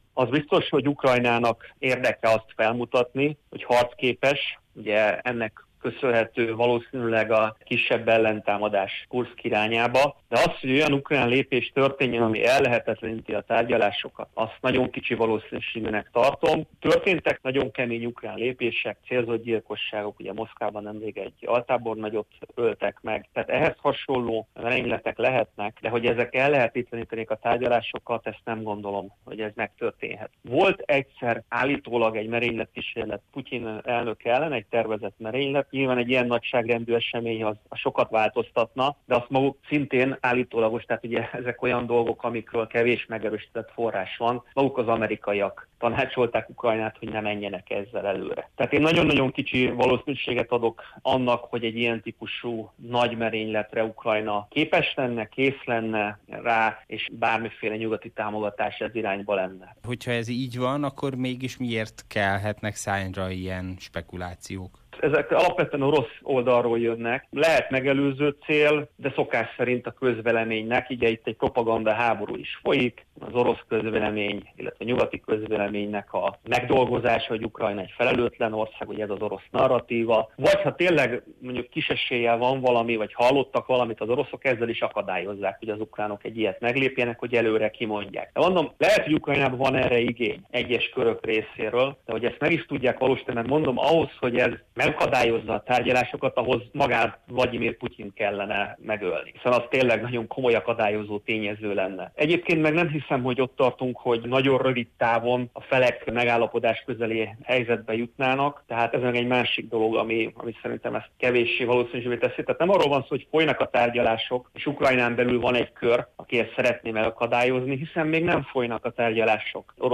interjúja